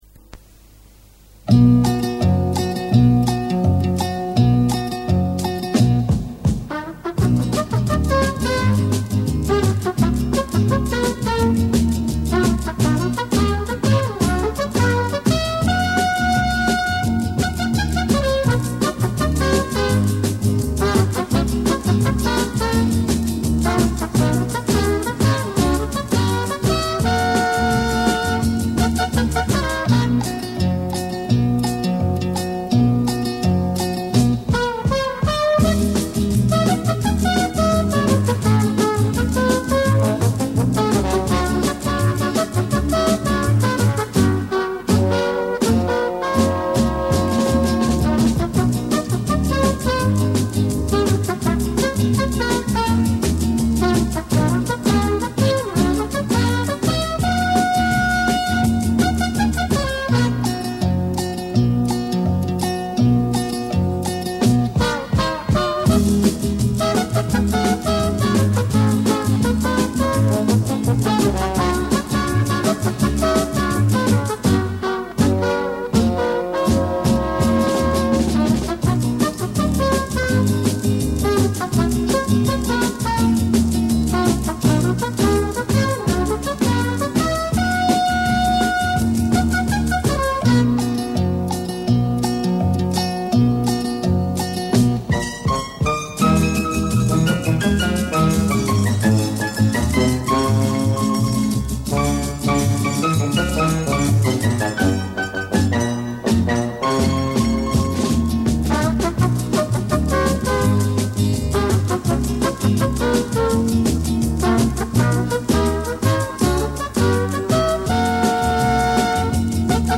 [28/4/2009]我收藏的几首七八十年代日本收录机试音带